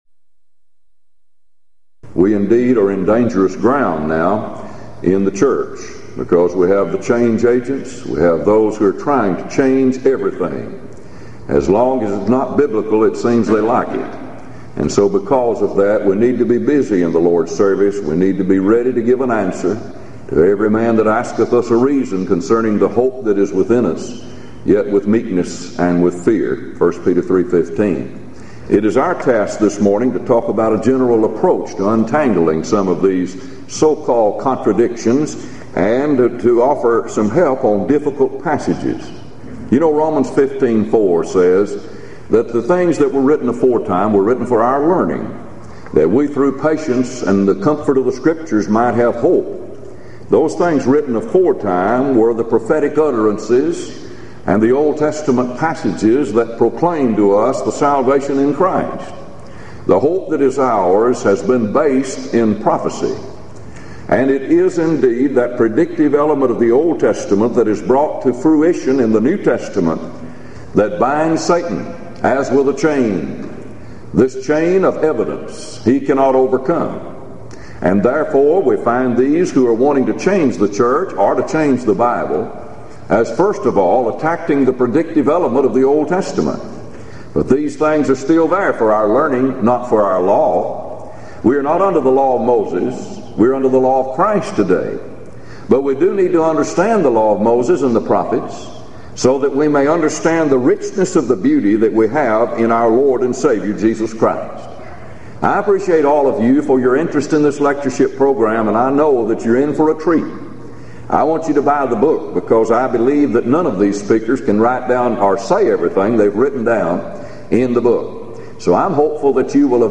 Event: 1995 Gulf Coast Lectures Theme/Title: Answering Alleged Contradictions & Problems In The Old Testament
lecture